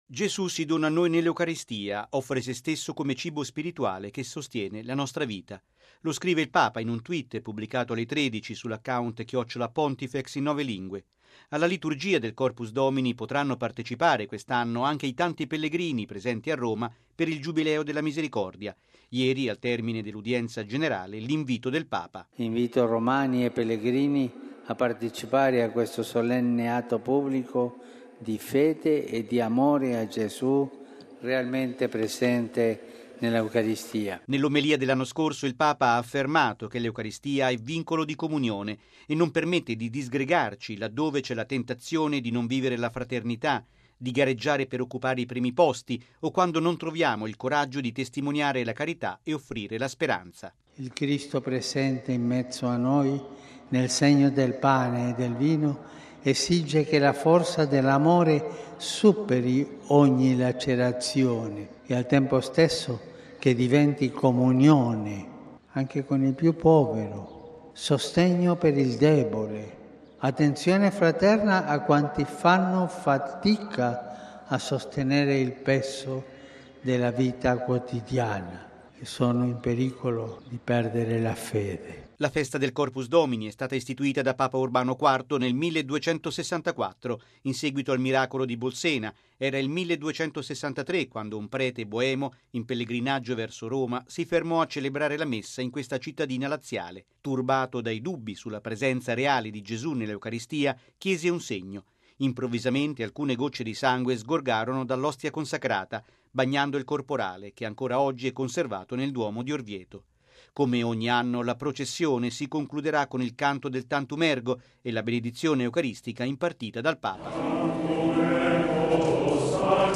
Bollettino Radiogiornale del 26/05/2016